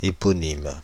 Ääntäminen
Ääntäminen France (Île-de-France): IPA: /e.pɔ.nim/ Paris: IPA: [e.pɔ.nim] Haettu sana löytyi näillä lähdekielillä: ranska Käännös Ääninäyte Substantiivit 1. eponym US Adjektiivit 2. eponymous Suku: f .